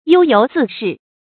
優游自適 注音： ㄧㄡ ㄧㄡˊ ㄗㄧˋ ㄕㄧˋ 讀音讀法： 意思解釋： 悠閑適意。